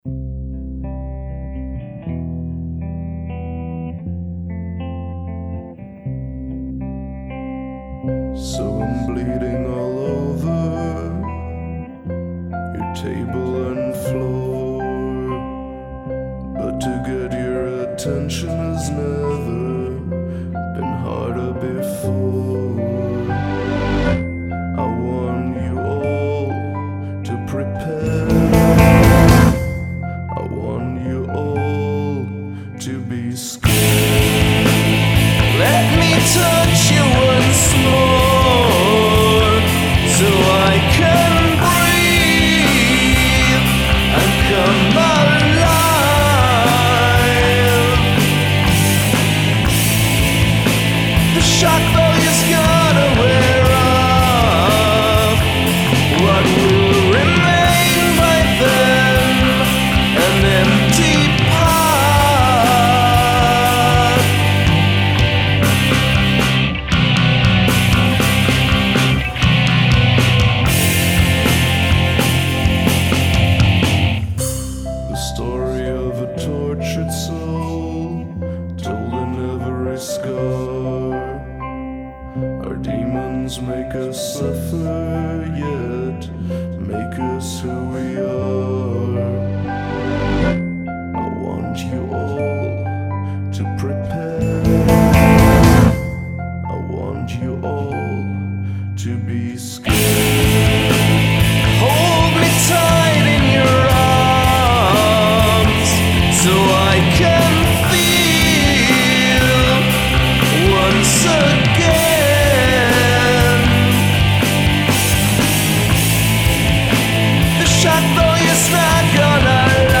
Ooh, that low vocal!